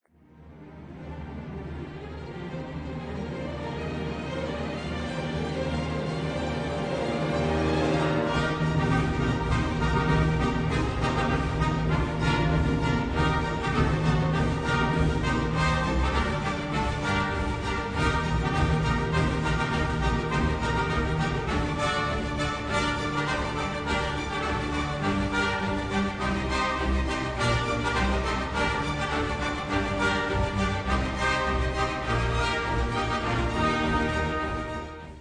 Karelia est une charmante petite suite absolument sans prétention et sans le moindre artifice de composition ; son morceau central, à la mélodie délicieusement archaïsante, évoque la rêverie élégiaque de quelque jeune pastoureau.
danses bondissantes et d’une gaieté irrésistible qui sont du plus pur orchestre sibélien, avec ses cordes rythmées et ses appels de fanfare.